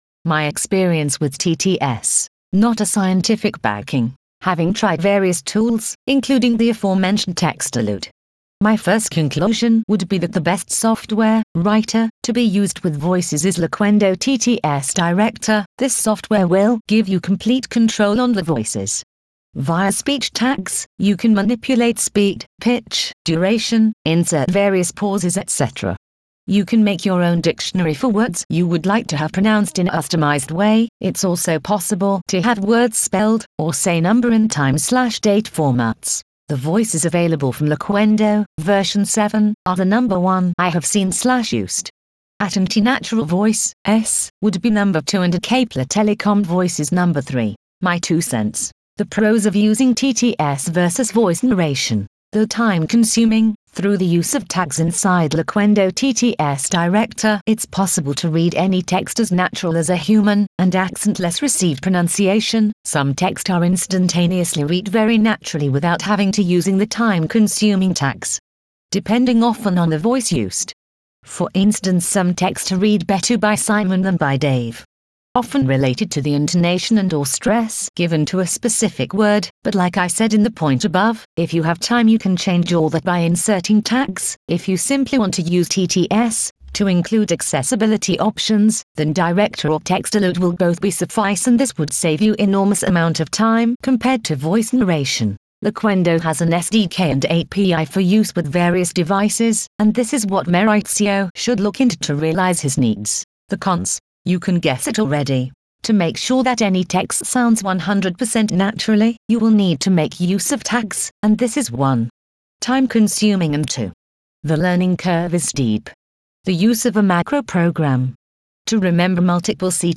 I have copied the text above into an Loquendo TTS Director and converted it into an MP3 file. No tags used and no alteration...so thequality isn't always great....but with some (non-time consuming) clean up and minimal tagging....it could sound a lot better kate.wav 11.9 MB Reply Reply